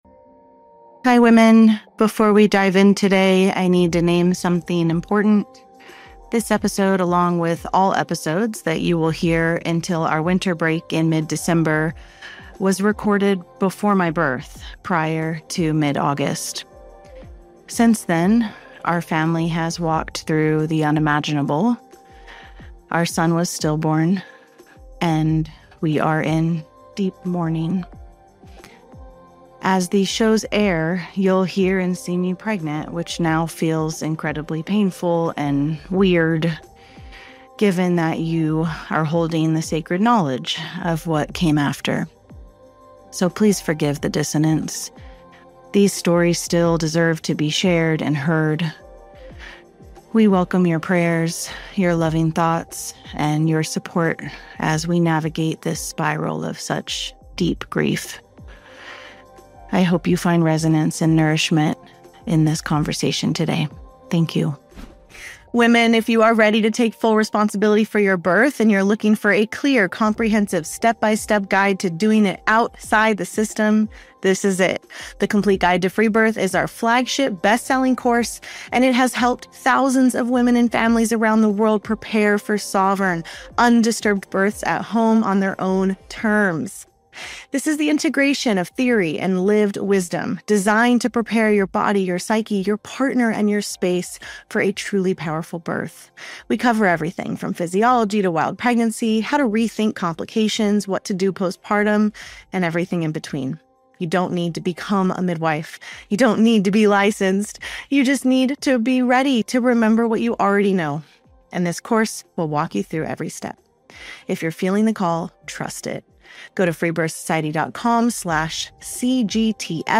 We met in person at Matriarch Rising Festival, and in this conversation, we explore what it means to truly live the lessons that birth offers us.